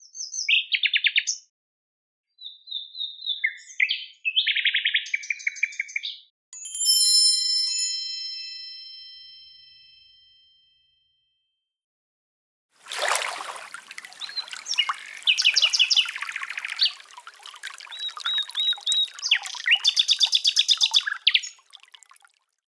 Zwitscherbox Lily Bells Mossling, nachtegaal met zachte klanken
Een zacht geluid dat meteen rust brengt.
De Zwitscherbox Lily Bells Mossling laat je bij iedere beweging genieten van het heldere gezang van een nachtegaal, gecombineerd met fijne belletjes en lichte waterklanken. Elegant, rustig en bijna dromerig, een geluid dat je even stil laat staan.
• Helder en rustgevend nachtegaalgeluid
• Fijne belletjes en lichte waterklanken
Geluid nachtegaal met zachte klankaccenten